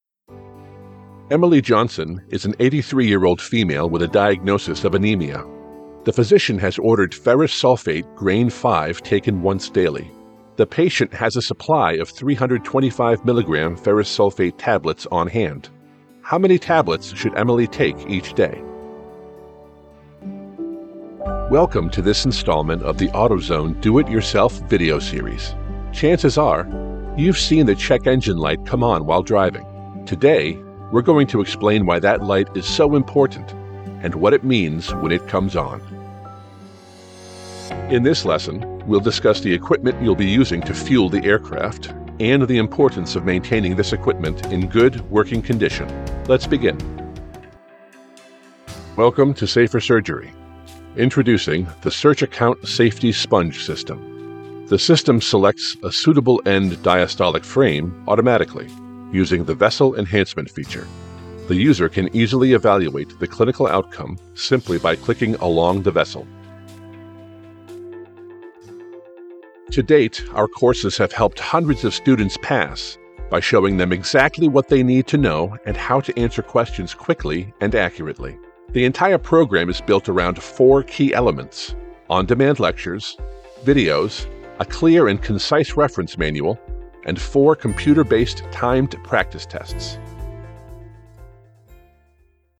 Authoritative and conversational voice to bring your words to life
E-Learning Demo
English - USA and Canada
Young Adult
Middle Aged